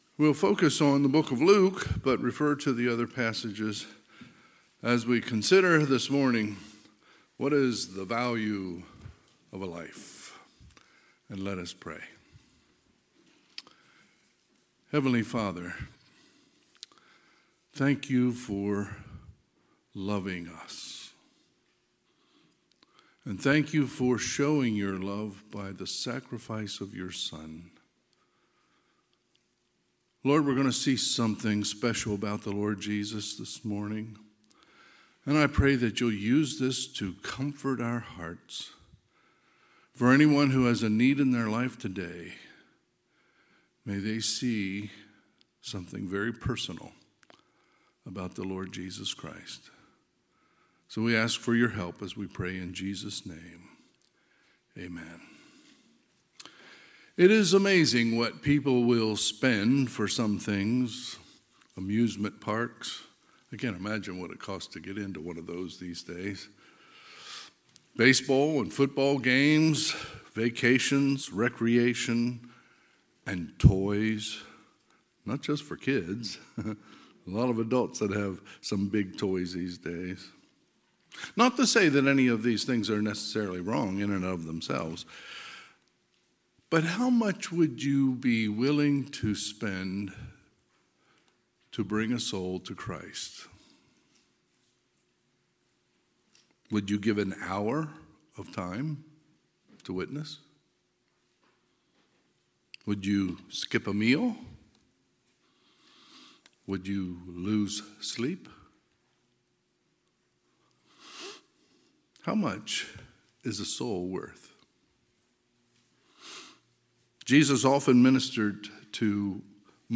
Sunday, June 4, 2023 – Sunday AM